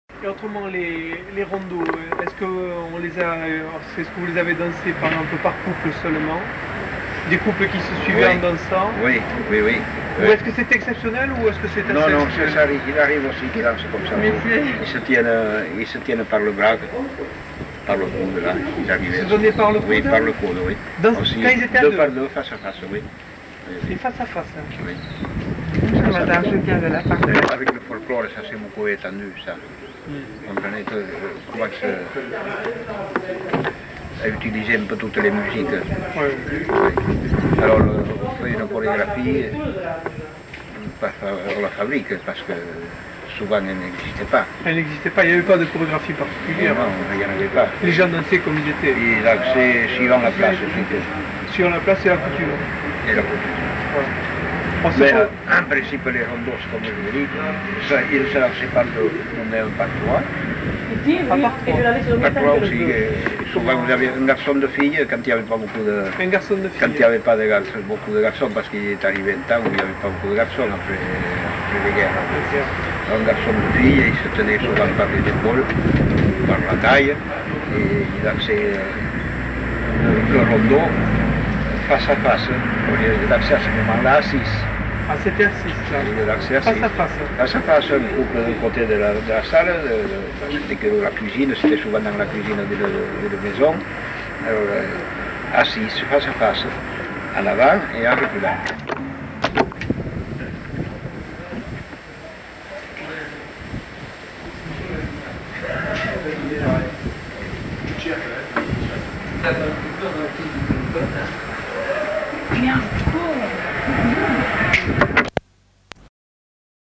Lieu : Mimizan
Genre : témoignage thématique